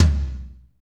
TOM F S L0RL.wav